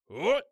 ZS蓄力7.wav
ZS蓄力7.wav 0:00.00 0:00.51 ZS蓄力7.wav WAV · 44 KB · 單聲道 (1ch) 下载文件 本站所有音效均采用 CC0 授权 ，可免费用于商业与个人项目，无需署名。
人声采集素材/男3战士型/ZS蓄力7.wav